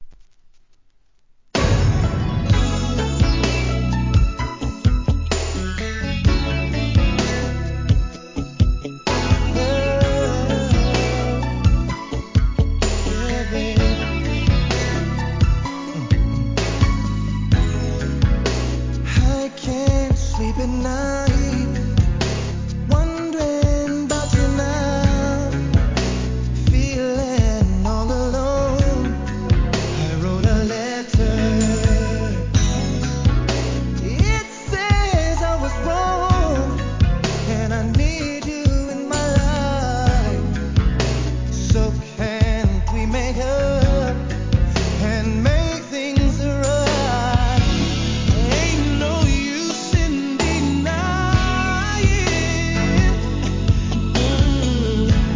1. HIP HOP/R&B
抜群の歌唱力で聴かせるミディアム・ナンバー!!